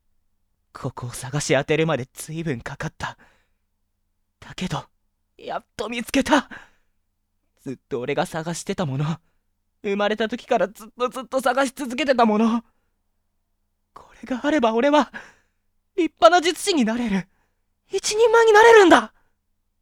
・元気で明るく行動力がある
【サンプルボイス】